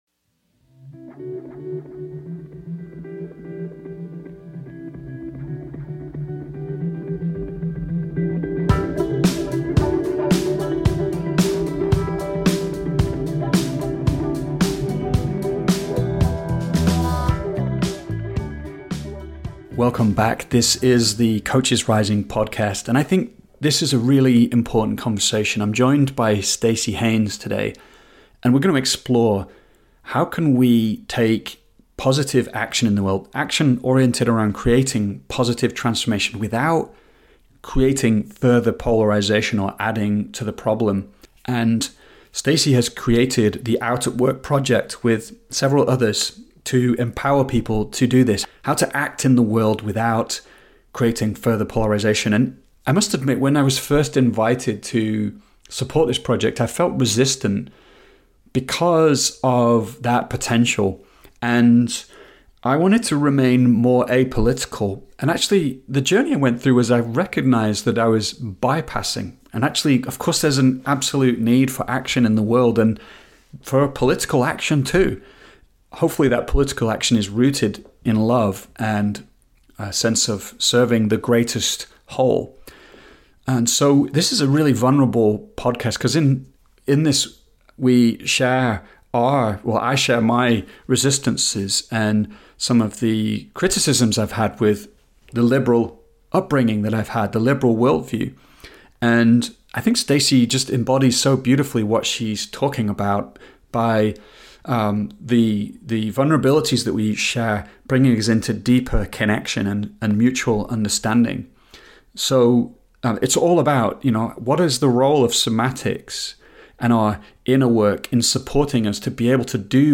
Our understanding of how our brains work continues to evolve, as well as our understanding of how we create meaning. How can we use advances in cognitive science to help our coaching clients? In this conversation